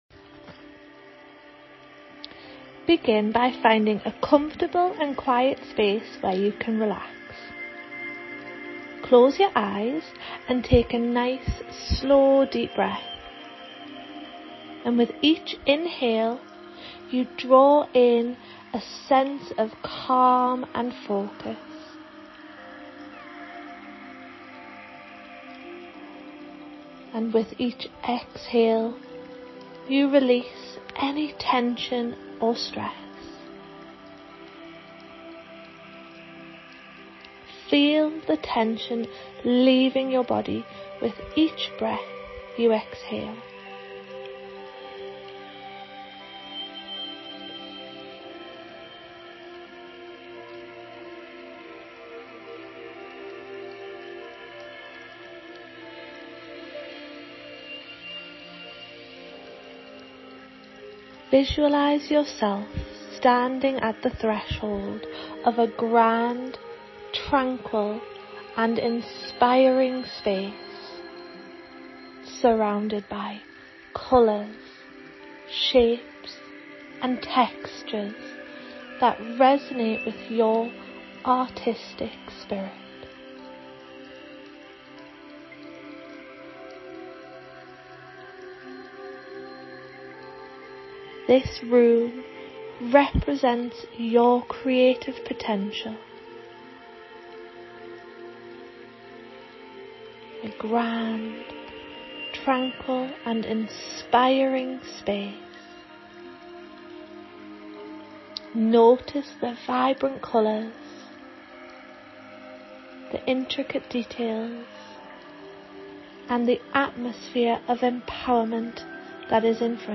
Creative-Hypnotherapy.mp3